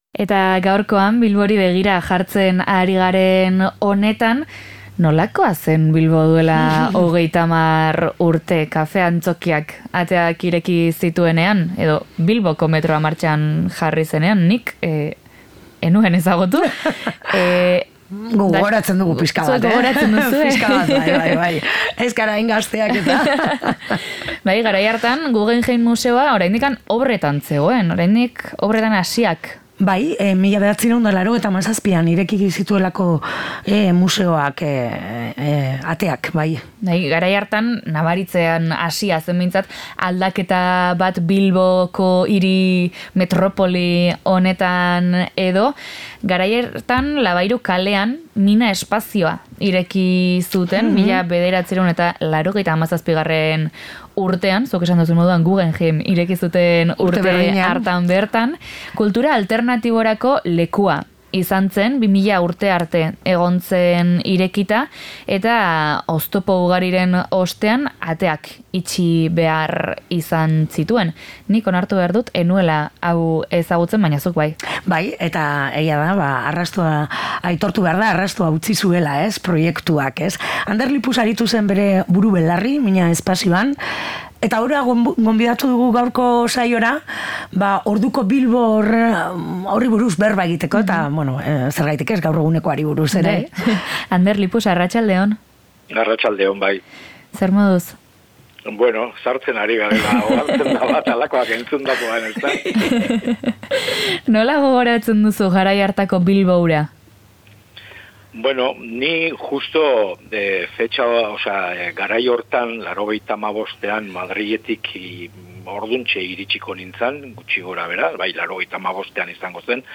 Horregatik gaur Zebrabidea Bilbo Hiria irratiko estudiotik egin dugu. Done Bikendi kaleko zazpigarren solairutik. Ekosistema horren bueltan, 1997an Mina Espazioa sortu zen.